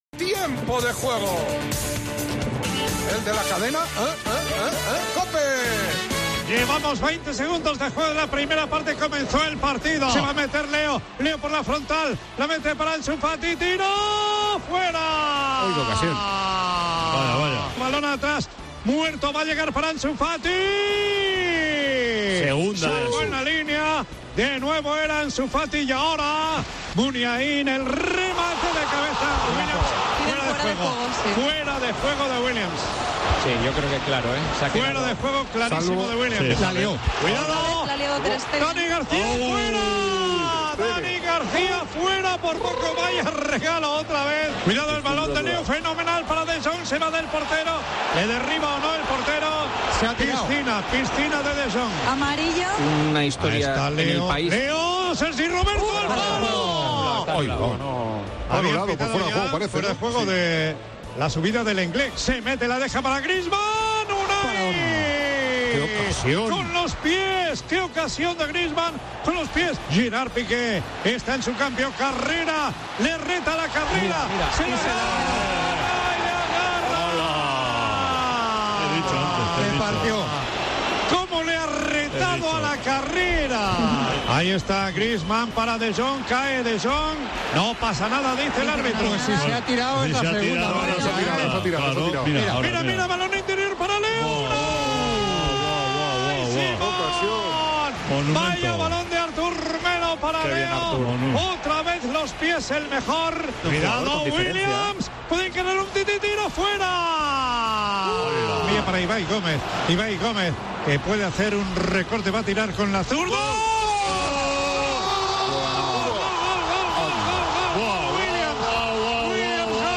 Així va sonar la derrota a la Copa del Rei a Tiempo de Juego amb narració